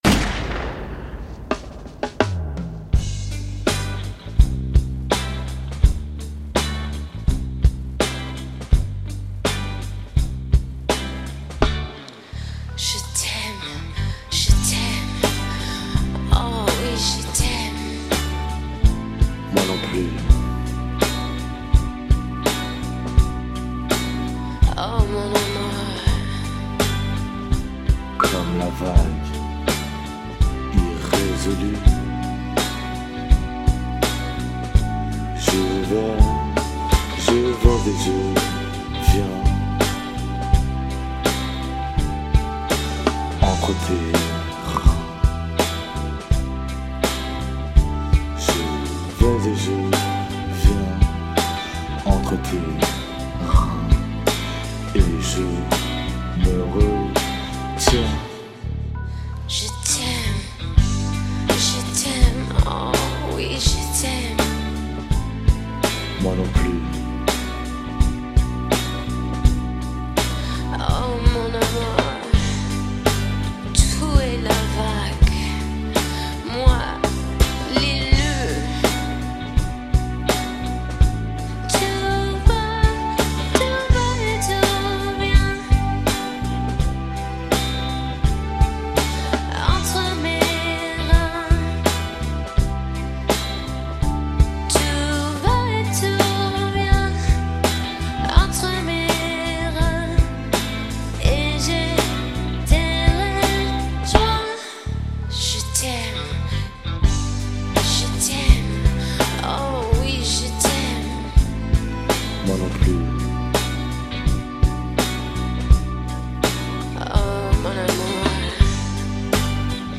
Pop / Rock / Jazz / Folk